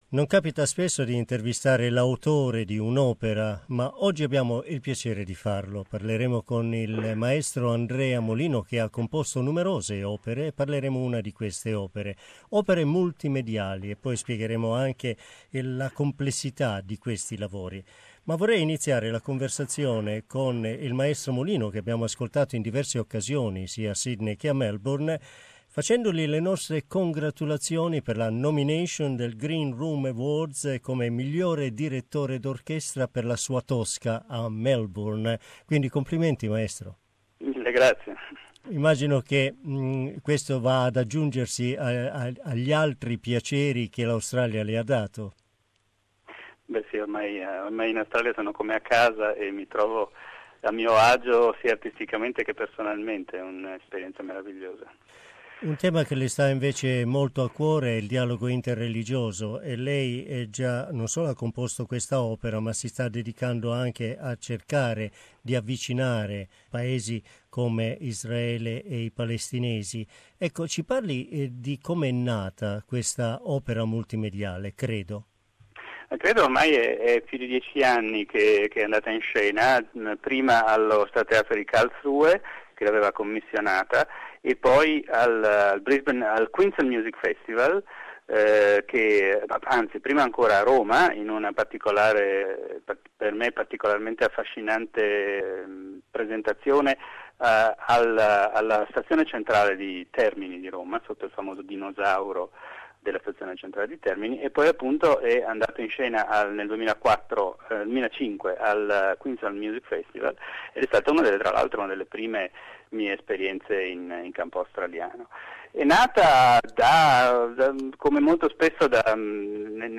In this interview, Maestro Molino tells how "Credo" was created, produced and performed as a multimedia spectacle.